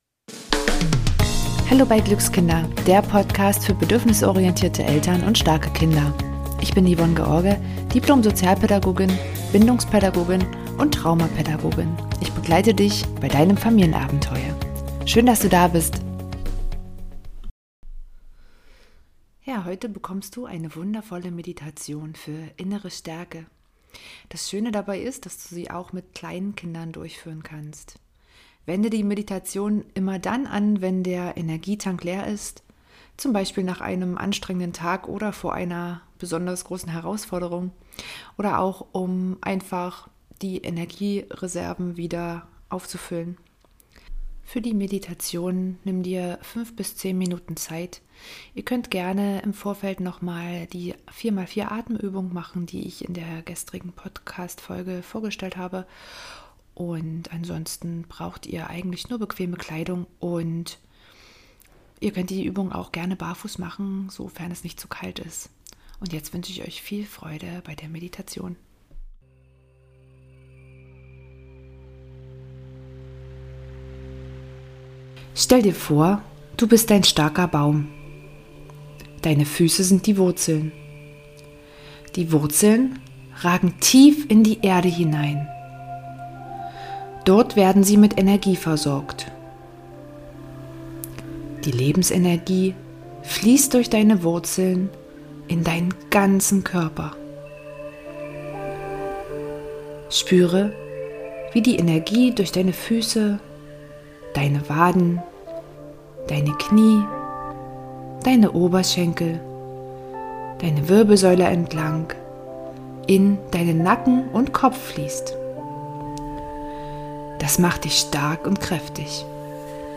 Stehmeditation für Kinder